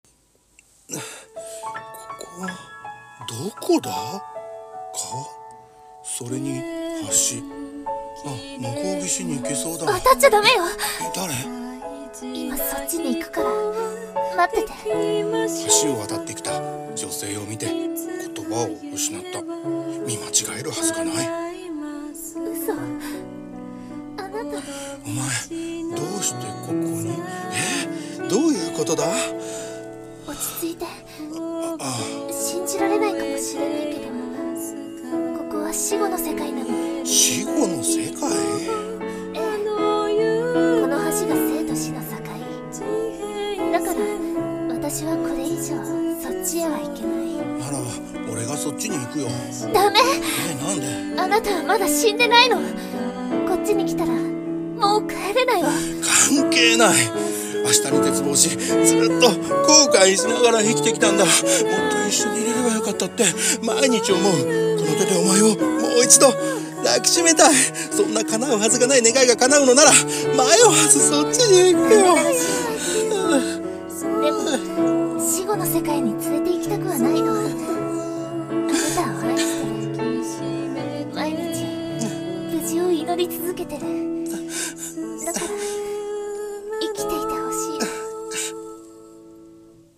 声劇【Geliebte】※歌入り台本